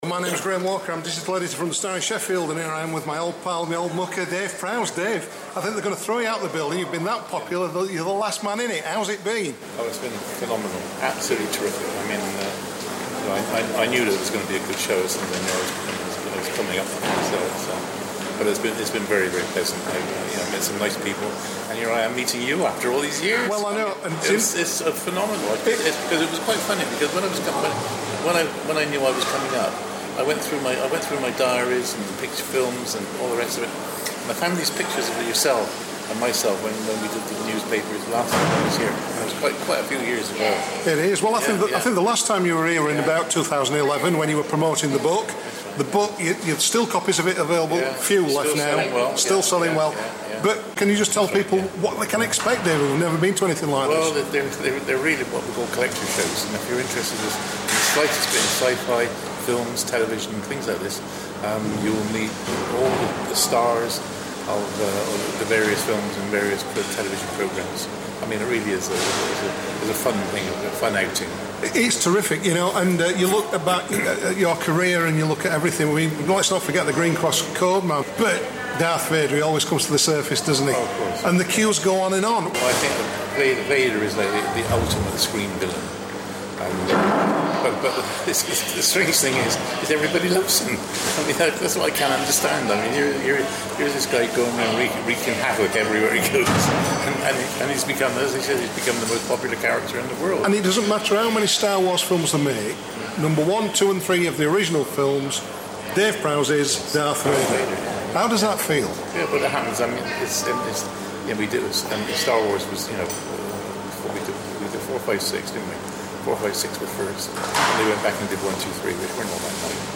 Interview: Darth Vader star Dave Prowse
Star Wars legend, Darth Vader star Dave Prowse, tells us what surprises him most about the public reaction to the greatest big screen villain of all time. We chatted at Sheffield Film and Comic Con 2014, at the Motorpoint Arena.